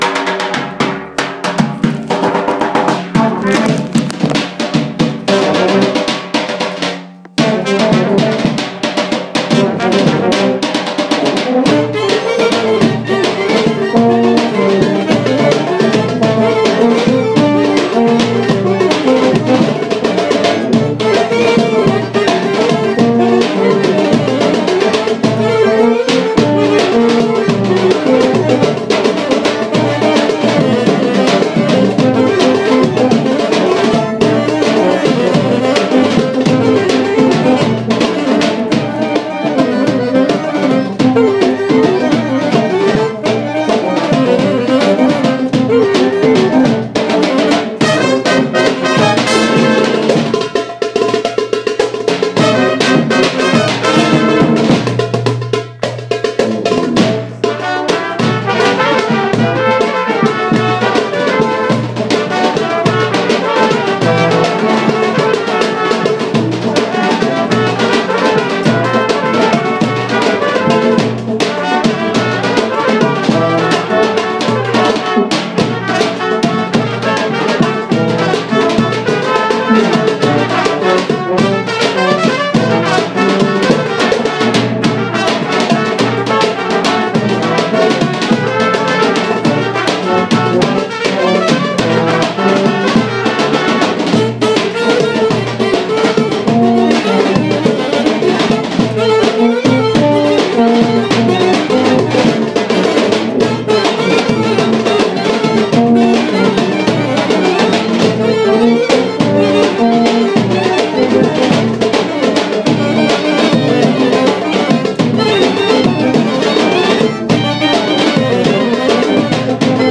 28. Oriental Cocek (D hij)
Rehearsal